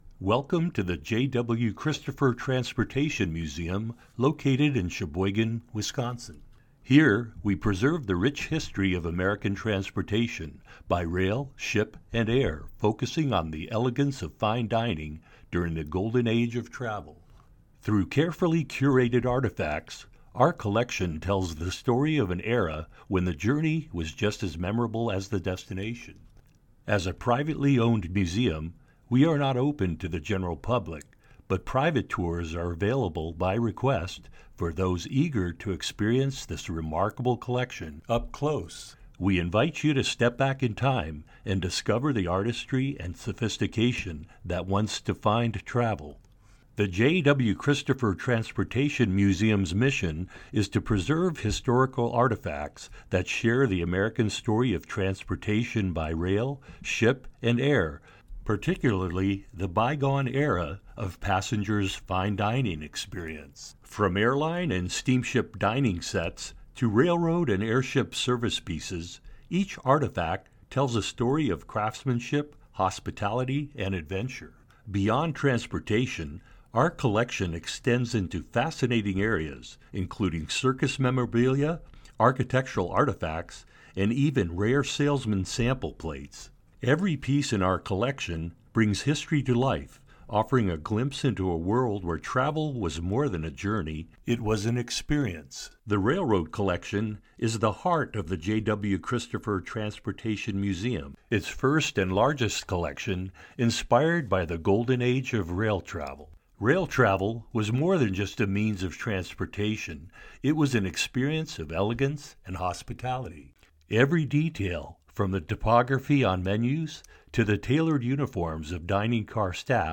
My voice is described as rich, warm, mature and versatile. It conveys a wide range of emotions and tones for radio & TV commercials, corporate narrations, documentaries, movie and video game trailers, podcasts and telephone prompts.